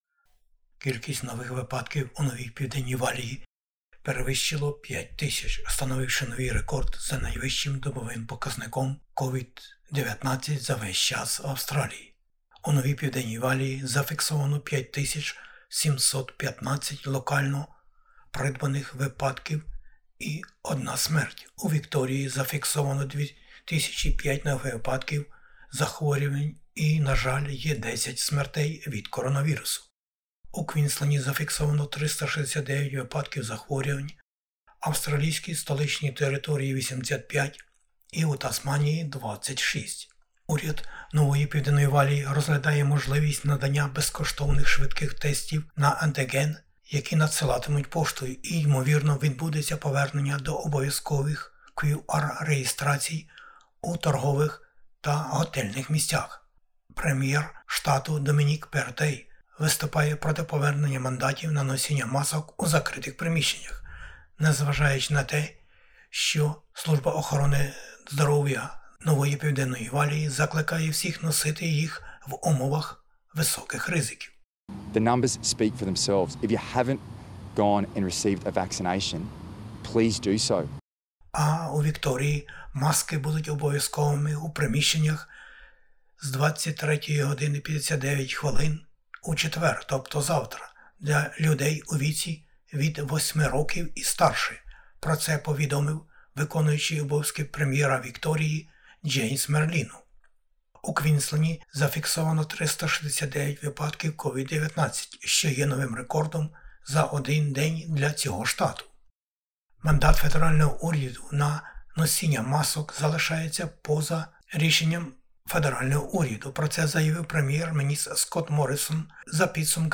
SBS бюлетень новин станом на 23 грудня 2021-го року. Рекордна кількість заражень у Новій Південній Валії.